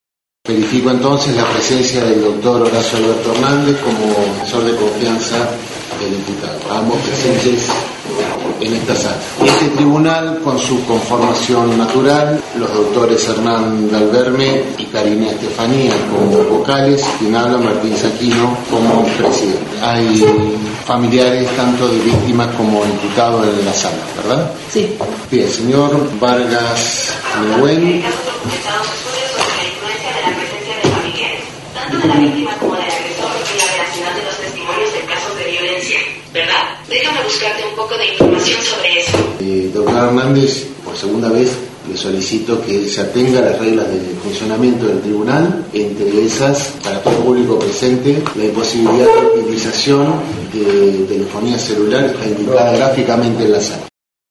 IA